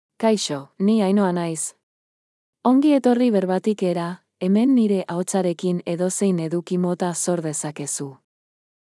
Ainhoa — Female Basque AI voice
Ainhoa is a female AI voice for Basque.
Voice sample
Listen to Ainhoa's female Basque voice.
Female